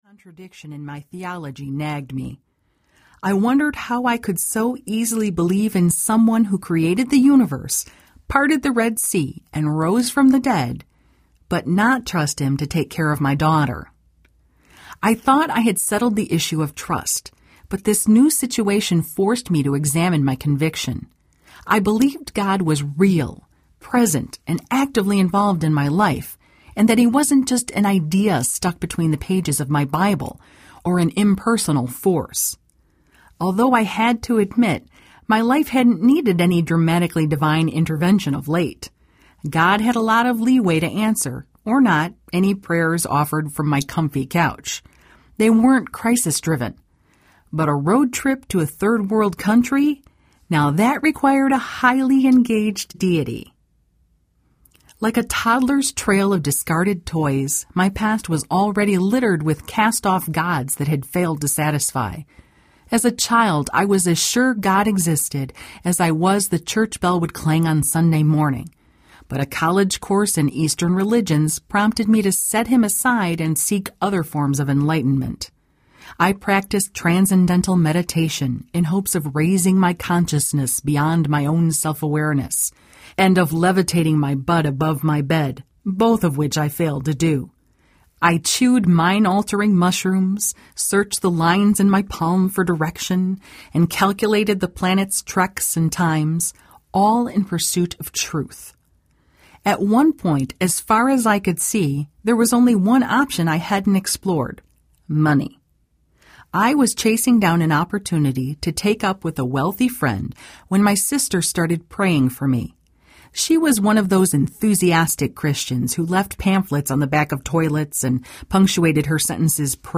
Call of a Coward Audiobook
Narrator
4.05 Hrs. – Unabridged